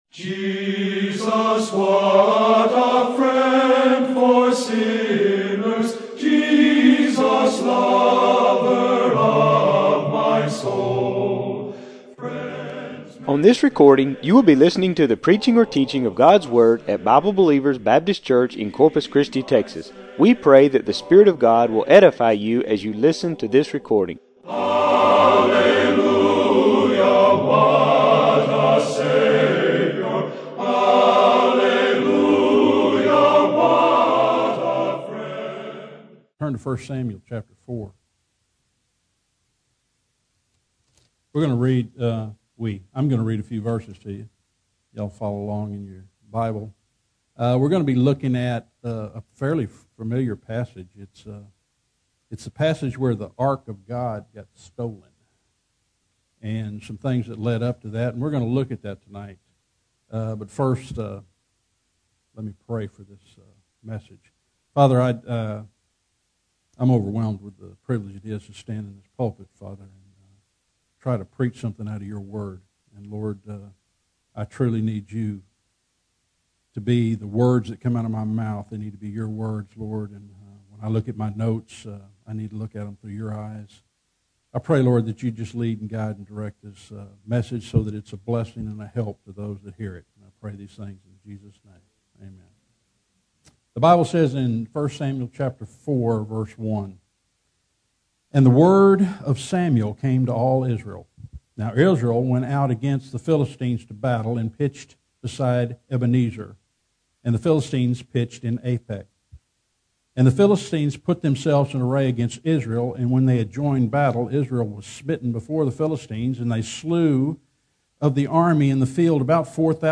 Israel ended up putting God in a box with their attitude about the ark. This sermon shows you how Christians do the same thing to God. By identifying what went wrong with Israel, this text shows you how to avoid the same mistakes in your life.